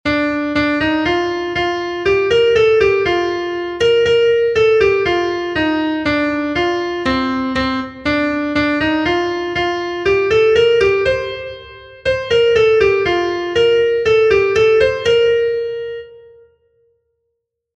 Erlijiozkoa
Gipuzkoako parrokietan, bezperetako goratzarre gisa, kantatzen da.
A-B